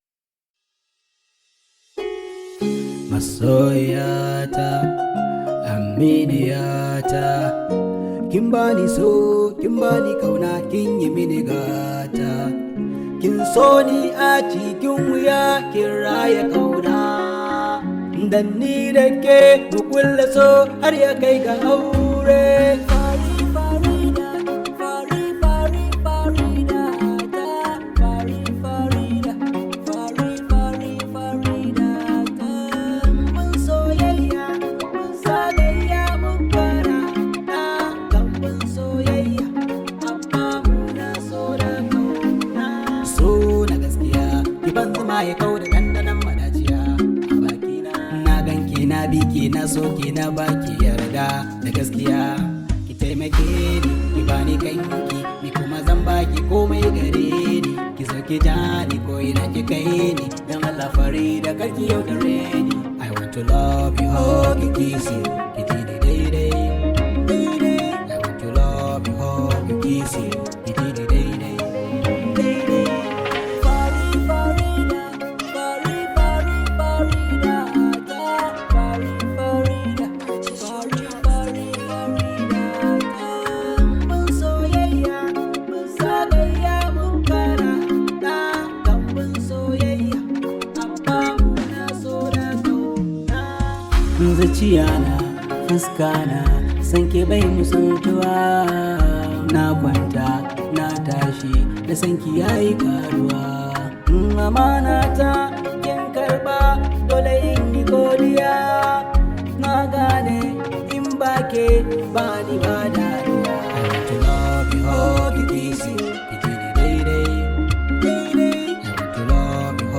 top rated Nigerian Hausa Music artist
high vibe hausa song